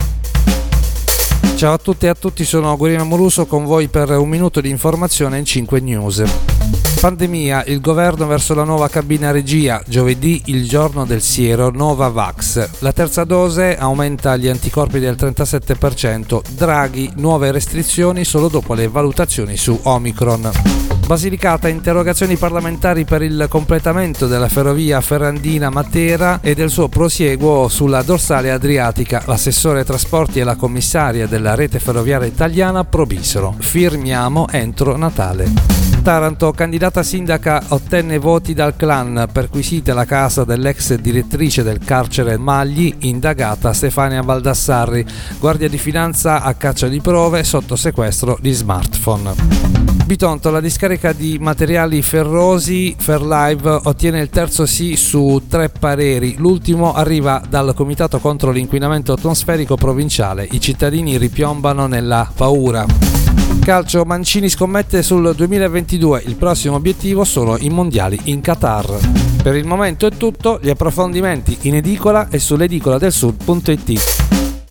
Giornale radio alle ore 7.